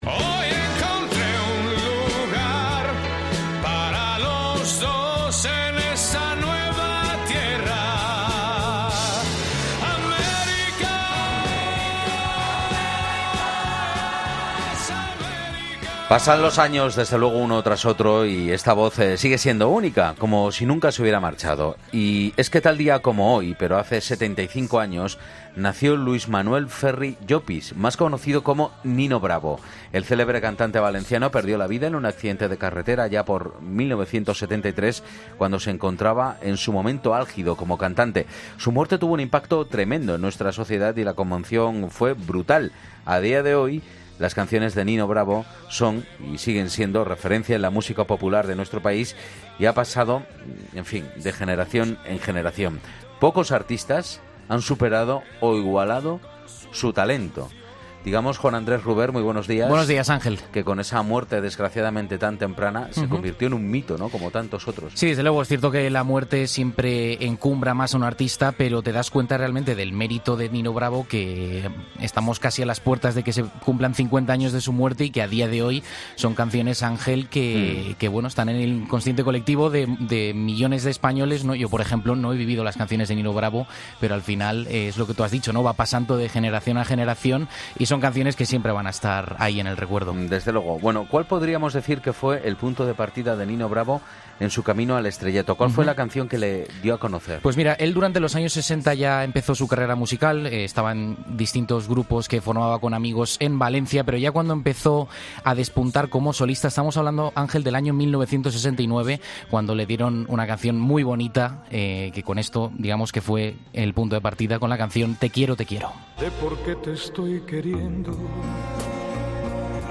El cantante valenciano habría cumplido este sábado 75 años y 'La Mañana Fin de Semana' le rinde homenaje a través de su música